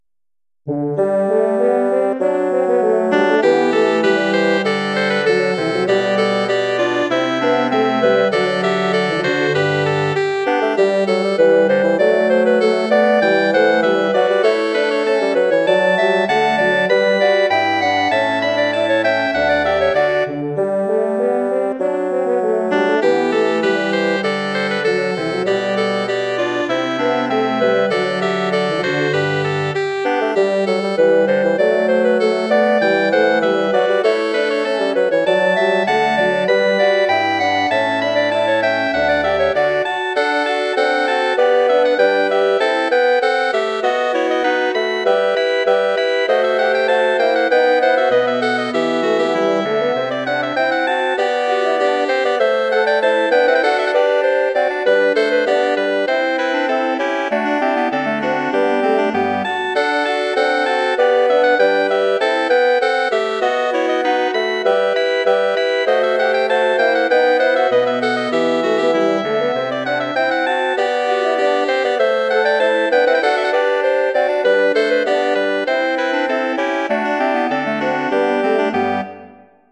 Bearbeitung für Holzbläserquartett
Besetzung: Oboe, Klarinette, Horn, Fagott
Instrumentation: oboe, clarinet, horn, bassoon